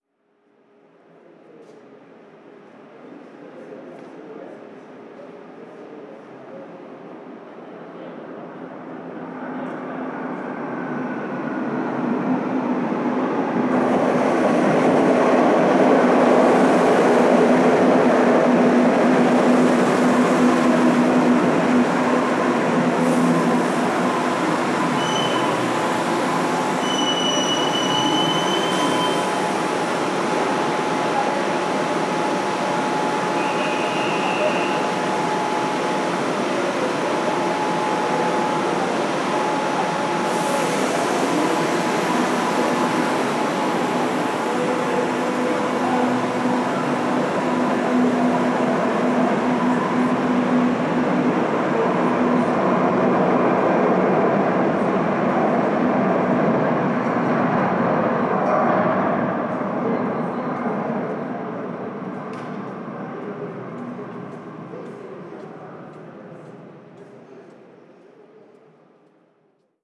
Metro llegando a una estación
metro
Sonidos: Transportes
Sonidos: Ciudad